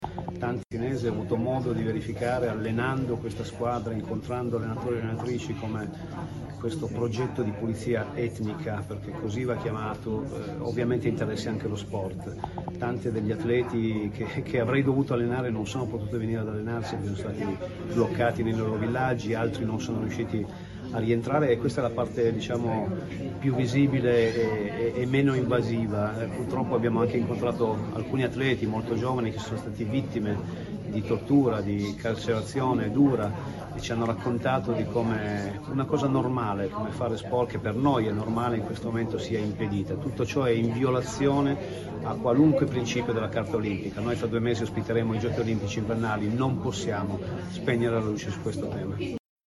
Allenare in Palestina - L’ex ct azzurro, Mauro Berruto, ha parlato della sua recente esperienza da allenatore a interim della Nazionale palestinese maschile di pallavolo. Ascoltiamo la dichiarazione rilasciata a La Presse.